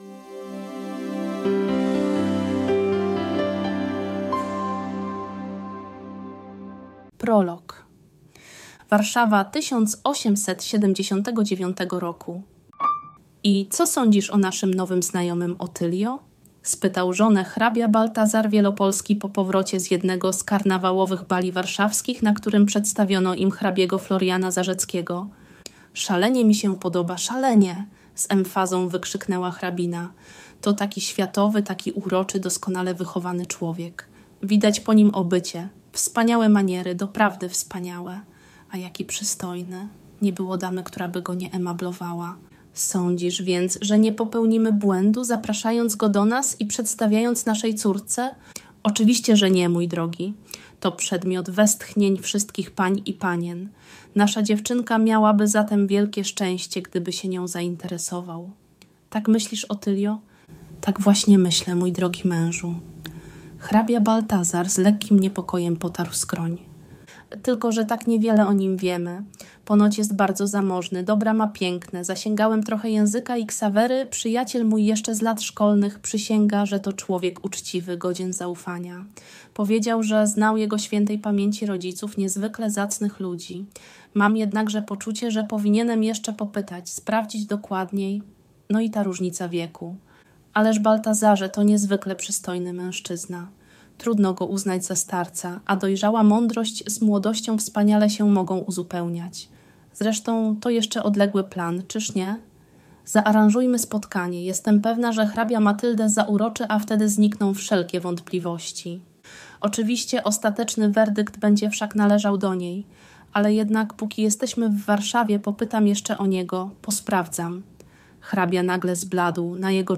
Księżyc nad jeziorem - audiobook